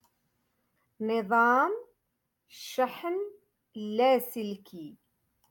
Moroccan Dialect-Rotation five-Lesson Sixty